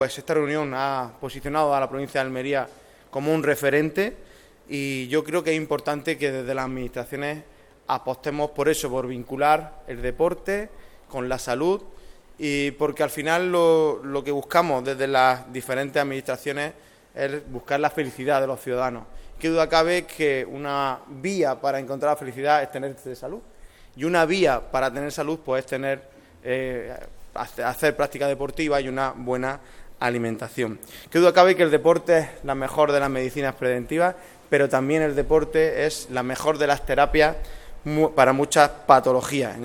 El Pabellón Moisés Ruiz ha sido el escenario ideal para presentar una jornada intensa en la que la ciencia médica dialoga con disciplinas tan diversas como la neurocirugía, la preparación física, el deporte de élite o incluso la música y la tauromaquia.